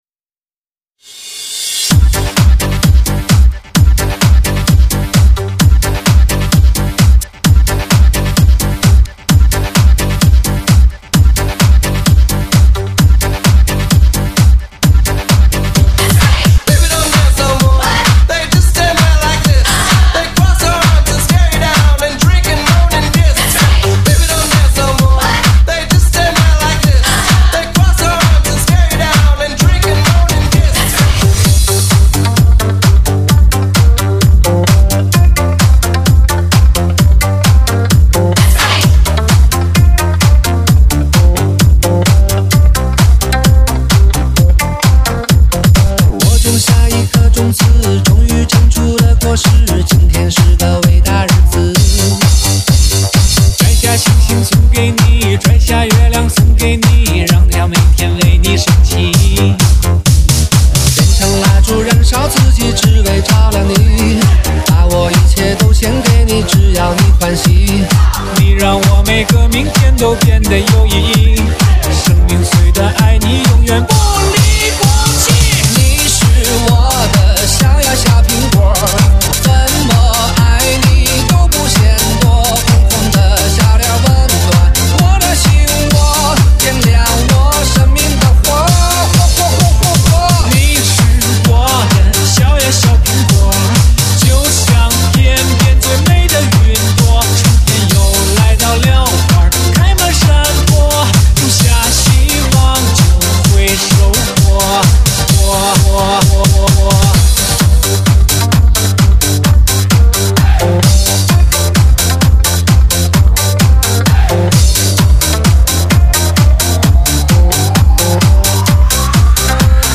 全方位多位环绕
发烧老情歌 纯音乐